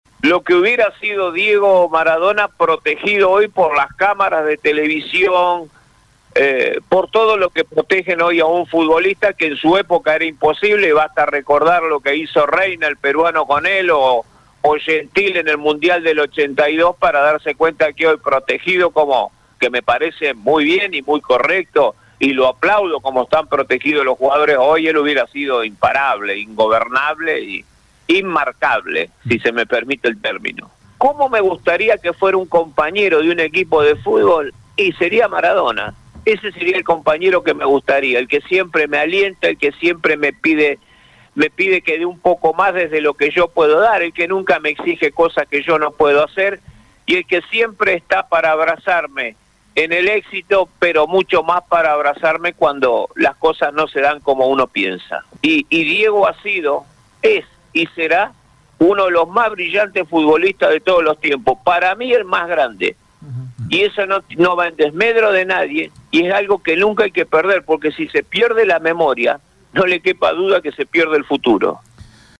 En Radio EME Deportivo dialogamos